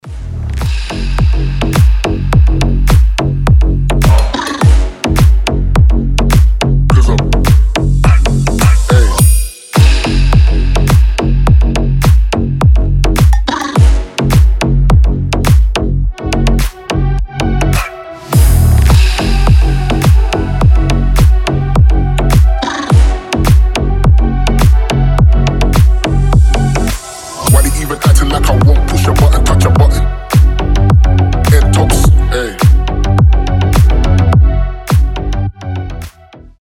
• Качество: 320, Stereo
басы
G-House
Стильный, четкий, громкий вызов